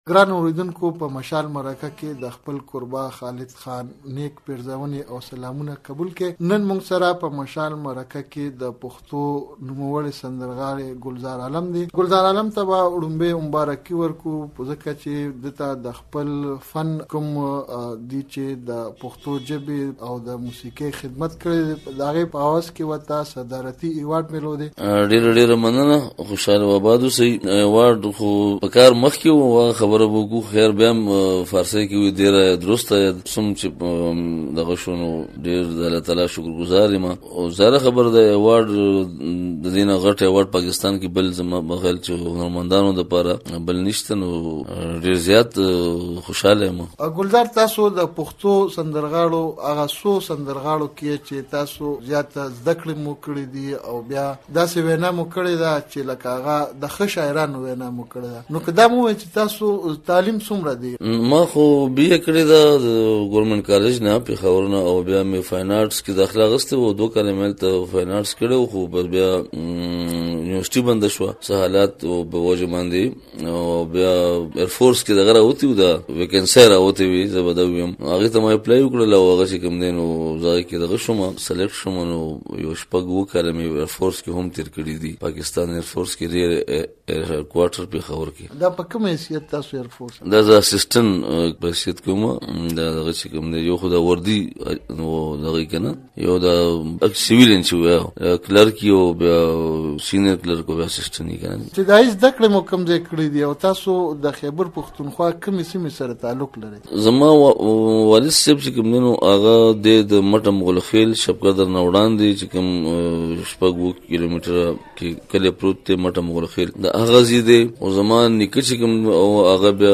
دمرکې پرمهال